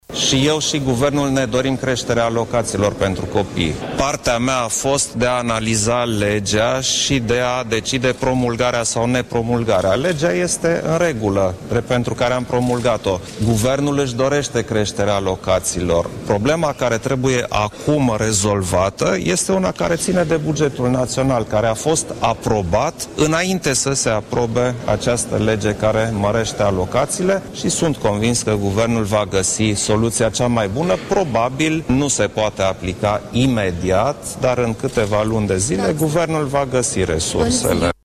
Preşedintele Klaus Iohannis a declarat, miercuri, că Legea privind dublarea alocaţiilor pentru copii ‘probabil nu se poate aplica imediat’, dar este convins că Guvernul va găsi resursele necesare în câteva luni.